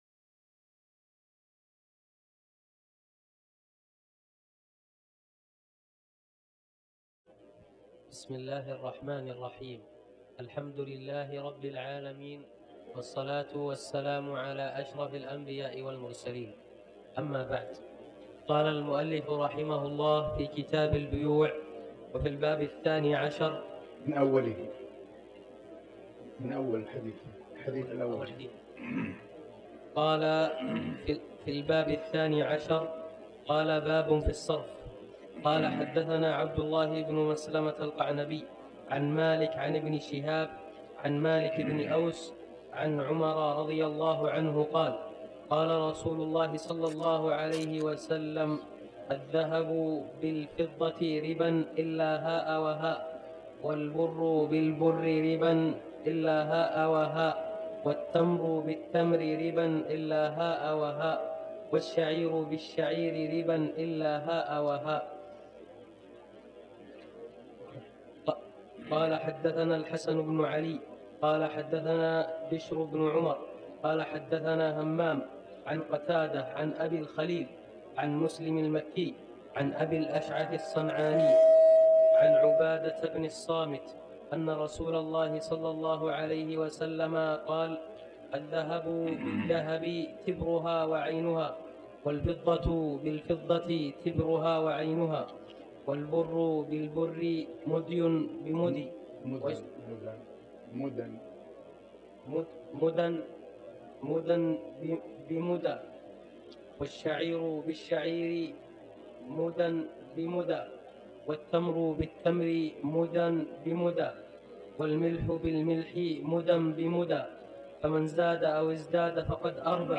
تاريخ النشر ١٥ شعبان ١٤٤٠ هـ المكان: المسجد الحرام الشيخ